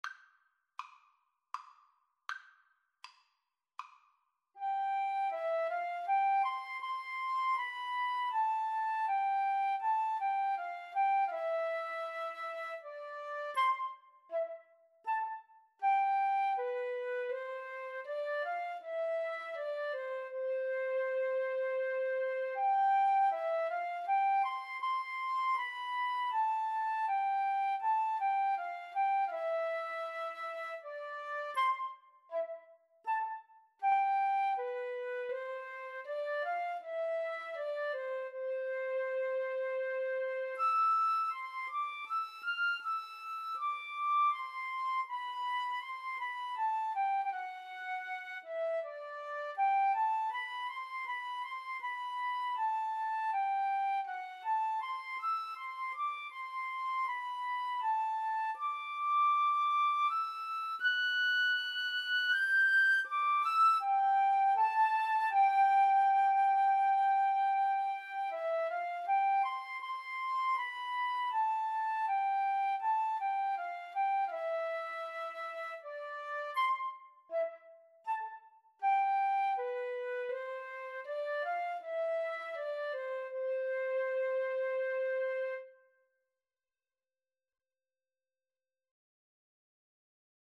Flute 1Flute 2
Andante
3/4 (View more 3/4 Music)
Classical (View more Classical Flute Duet Music)